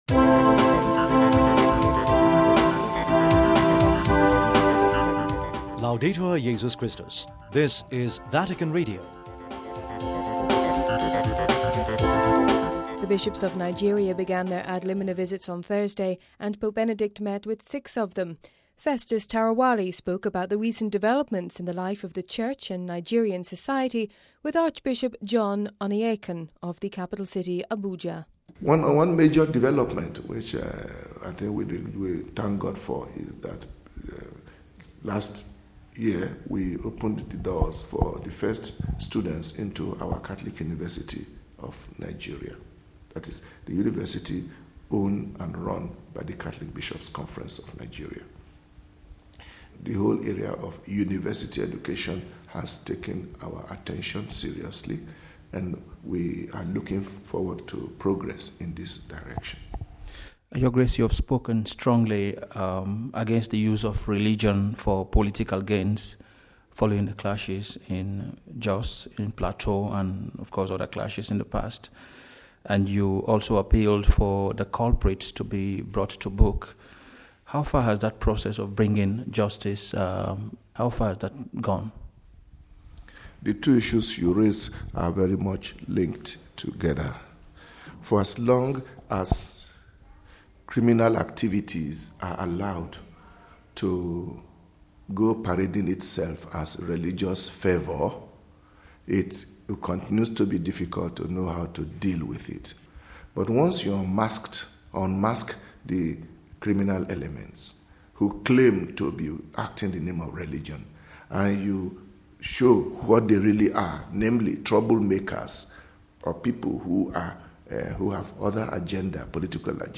Home Archivio 2009-02-05 16:28:27 Nigerian Bishops Begin Ad Limina Vists (05 Feb 09 - RV) Pope Benedict began meeting with bishops from Nigeria on Thursday. We spoke to the Archbishop of Abuja, Archbishop John Onaiyekan...